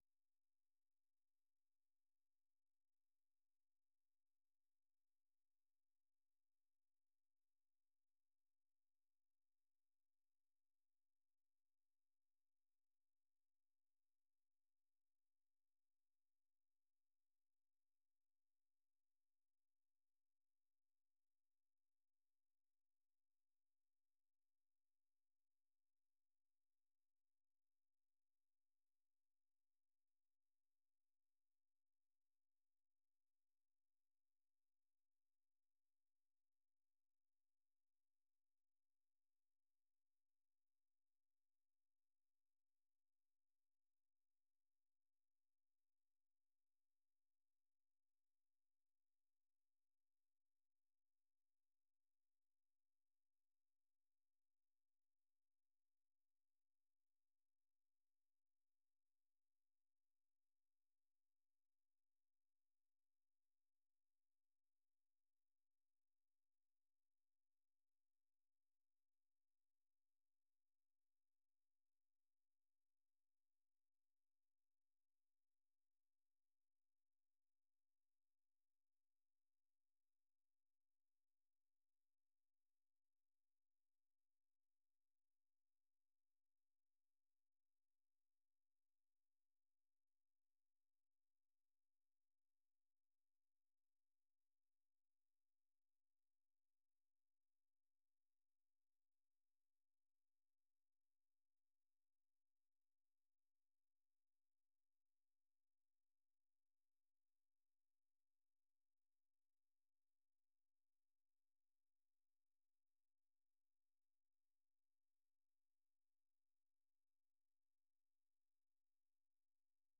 생방송 여기는 워싱턴입니다
세계 뉴스와 함께 미국의 모든 것을 소개하는 '생방송 여기는 워싱턴입니다', 저녁 방송입니다.